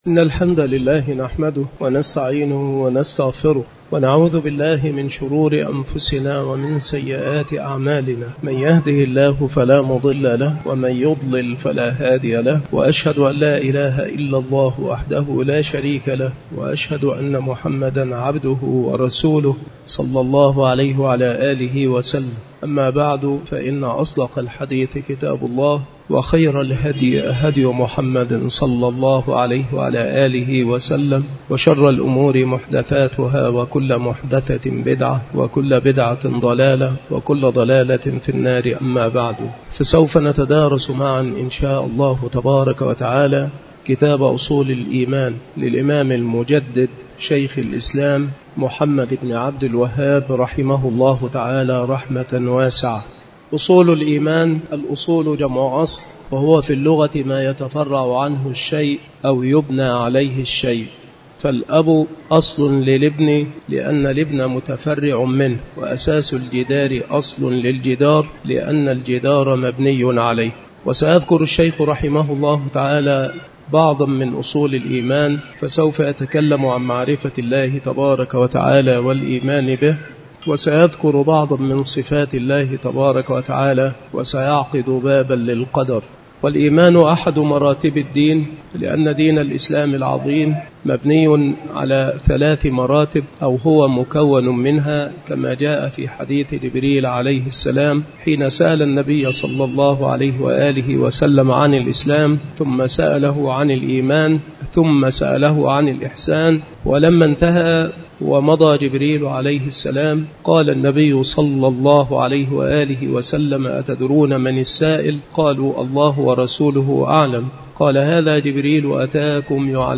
مكان إلقاء هذه المحاضرة بالمسجد الشرقي بسبك الأحد - أشمون - محافظة المنوفية - مصر عناصر المحاضرة : تعريف الأصل في اللغة. ما هو الإيمان؟ الدين على ثلاث مراتب. الإيمان في اللغة والشرع.